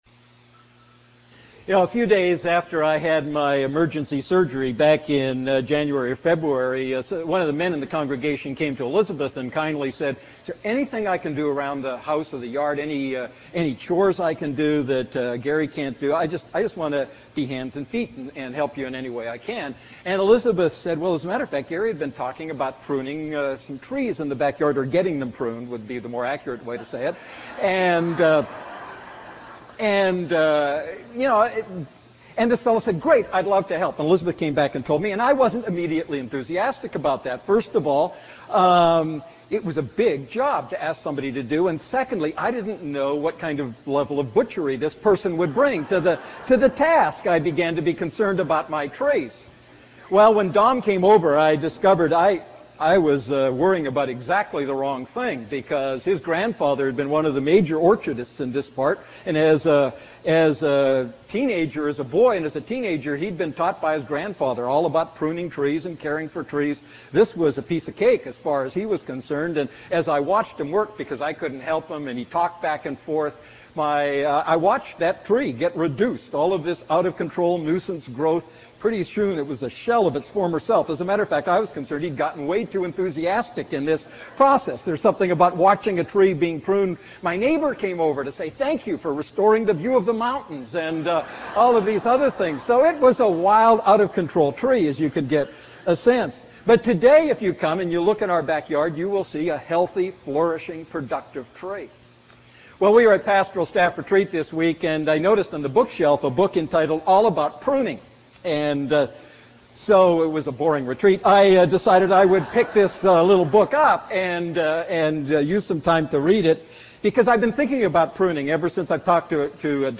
A message from the series "Family Matters."